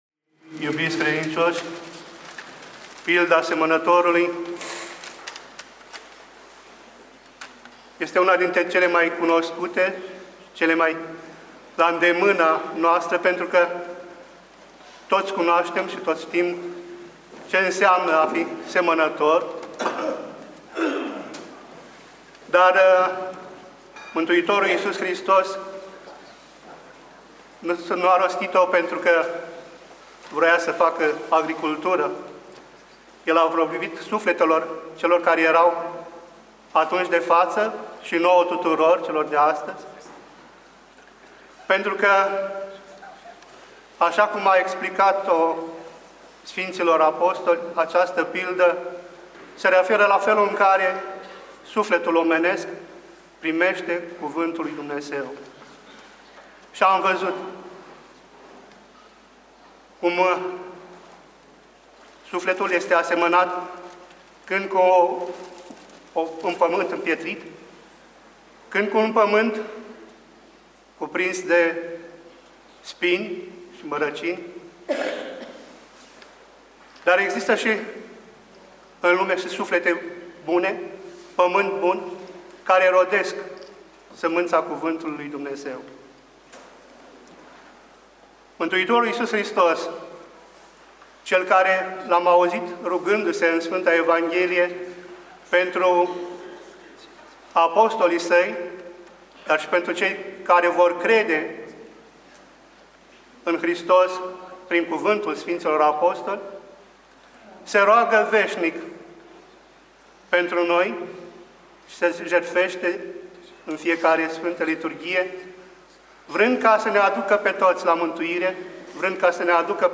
Arhivă de predici la Duminica a XXI-a după Rusalii - pilda semănătorului / ortodoxradio